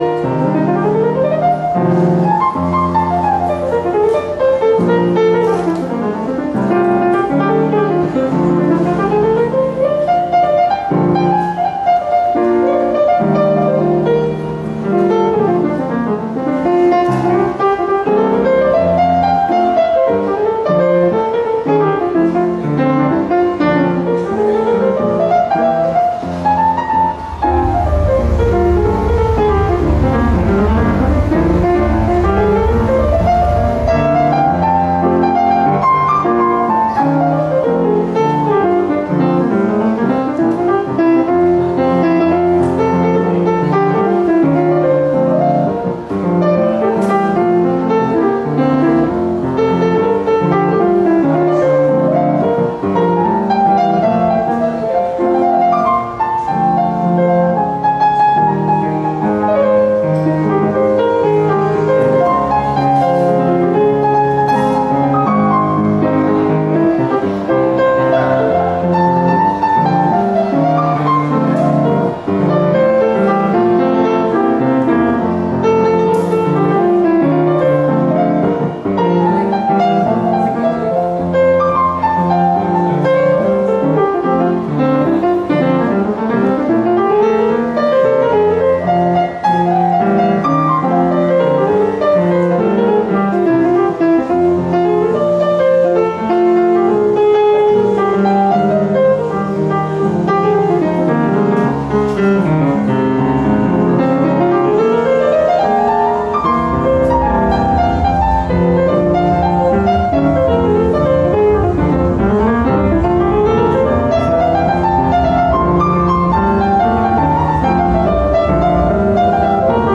ストリートピアノ